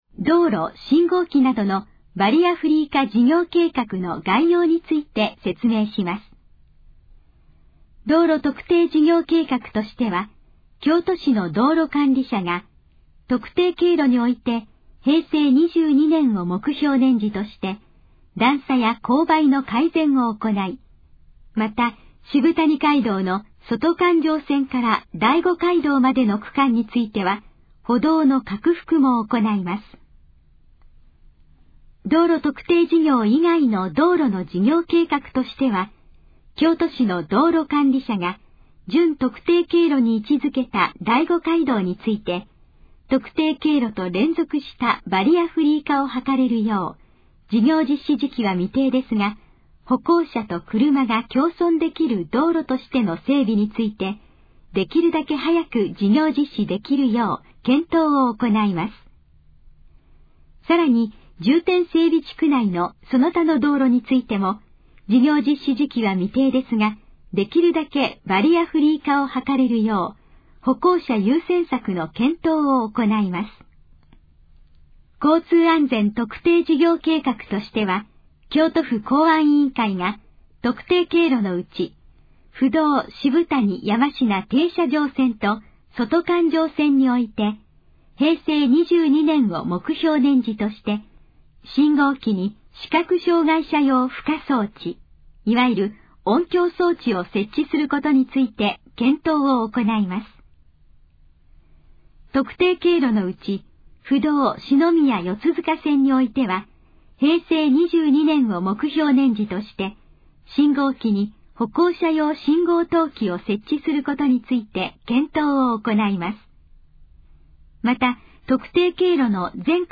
以下の項目の要約を音声で読み上げます。
ナレーション再生 約283KB